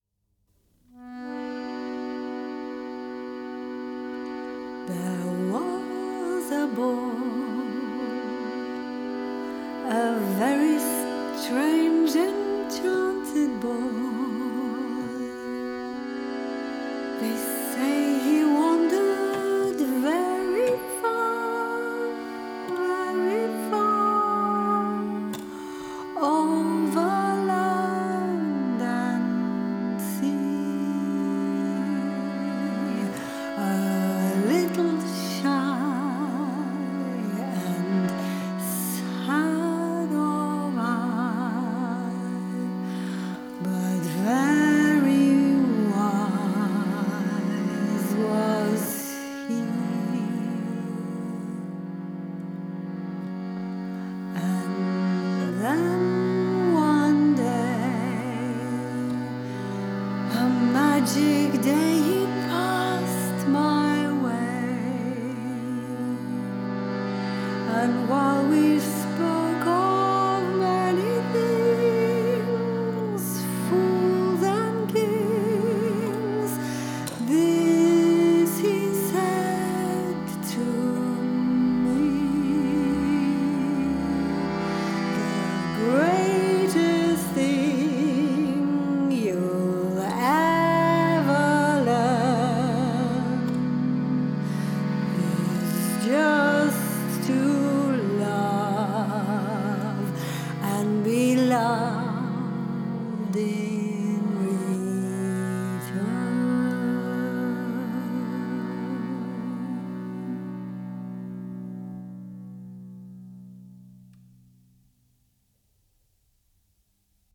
early music keyboard player
harmonium